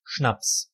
The English loanword "schnapps" is derived from the colloquial German word Schnaps [ʃnaps]
De-Schnaps.ogg.mp3